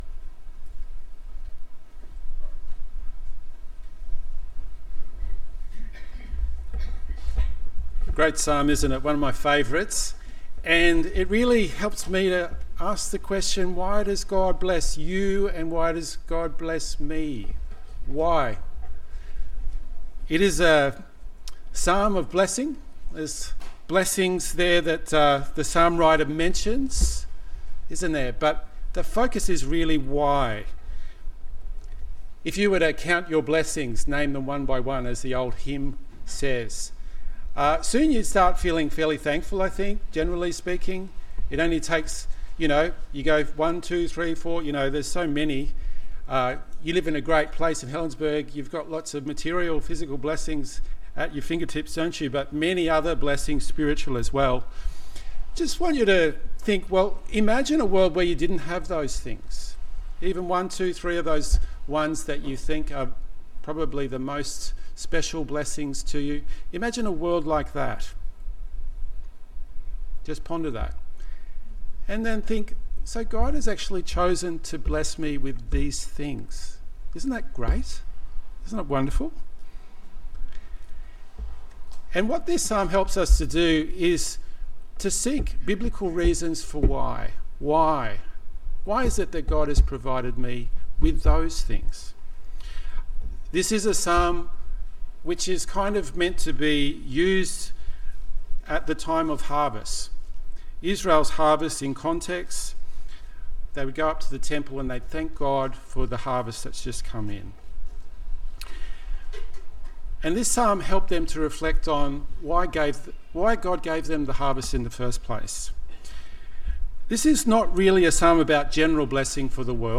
… continue reading 311 에피소드 # Christianity # Religion # Anglican # Jesus # Helensburgh # Stanwell # Park # Helensburgh Stanwell Park Anglican A Church # Stanwell Park Anglican A Church # Sermons